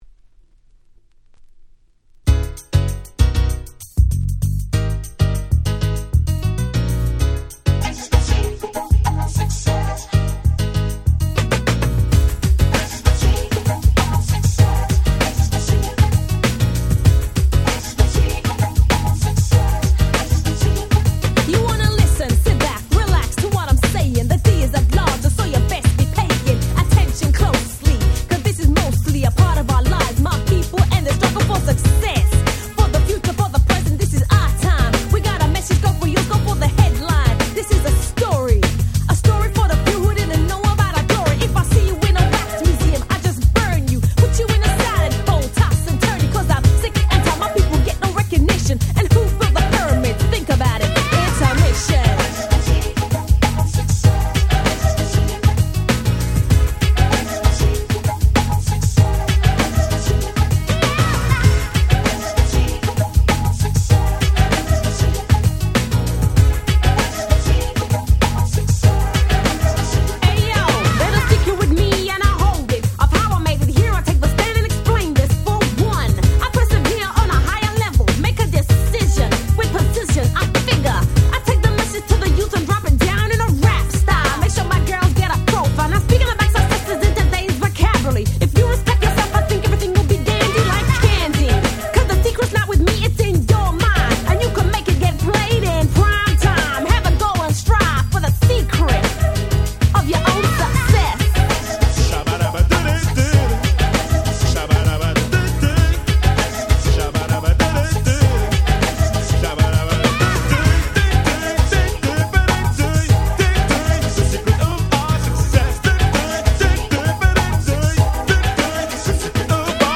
91' Smash Hit Hip Hop !!
90's キャッチー系